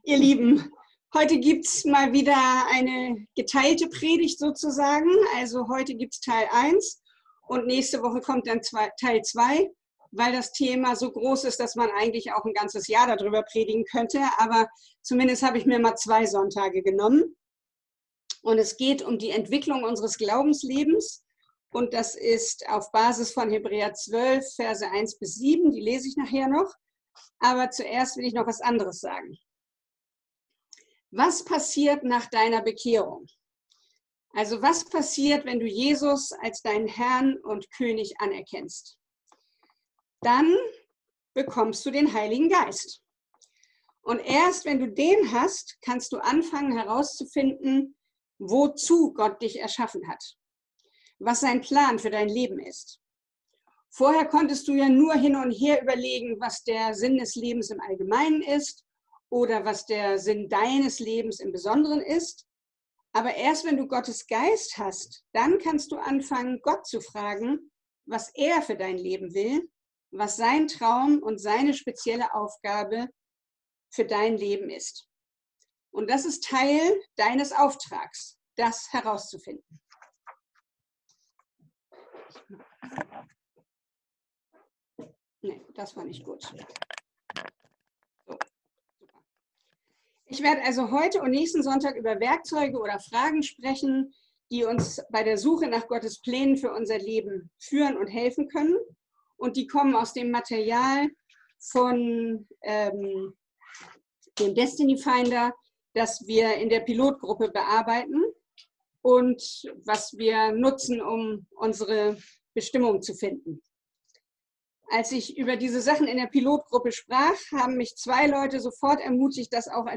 Serie: Predigt Gottesdienst: Sonntag %todo_render% « Pfingsten Gesitliche und persönliche Entwicklung